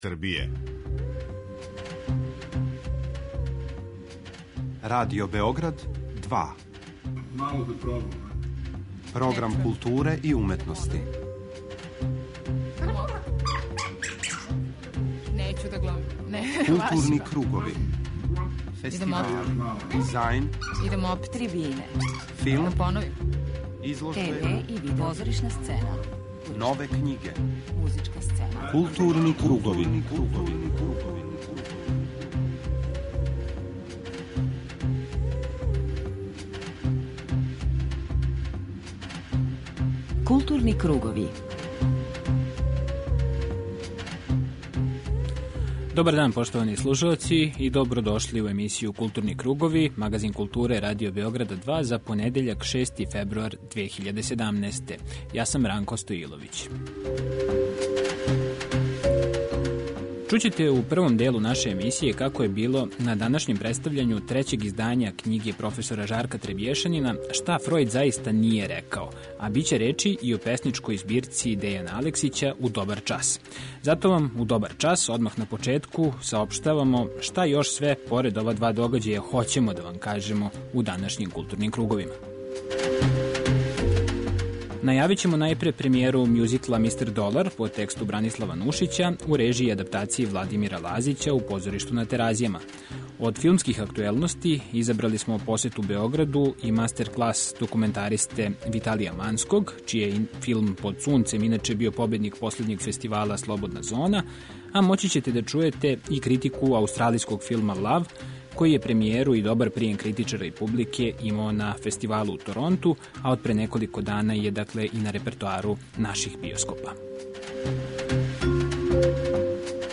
Од филмских актуелности изабрали смо посету Београду и мастерклас документаристе Виталија Манског, чији је филм Под сунцем био победник Фестивала „Слободна зона", а моћи ћете да чујете и критику аустралијског филма Лав, који је премијеру и добар пријем критичара и публике имао на фестивалу у Торонту, а од пре неколико дана је и на репертоару наших биоскопа.
преузми : 39.52 MB Културни кругови Autor: Група аутора Централна културно-уметничка емисија Радио Београда 2.